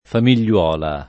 famigliola [ famil’l’ 0 la ]